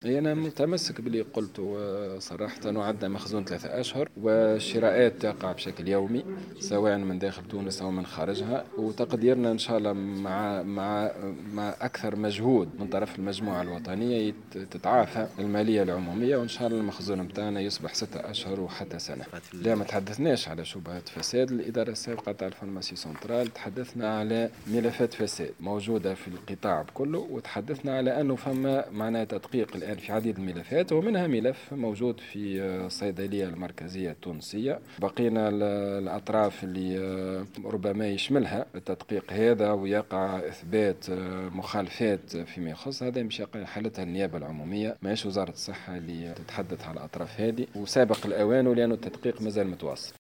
وأوضح في تصريح لمراسل "الجوهرة اف أم" على هامش أشغال المجلس الجهوي لولاية جندوبة، أن هناك ملفات فساد بالقطاع بشكل عام ومنها ملفات تخص الصيدلية المركزية ويتم حاليا التدقيق في هذه الملفات.